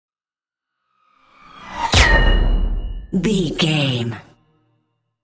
Sci fi whoosh to hit metal shot
Sound Effects
dark
futuristic
intense
woosh to hit